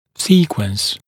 [‘siːkwəns][‘си:куэнс]последовательность, очерёдность